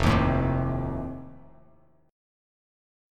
F7 Chord
Listen to F7 strummed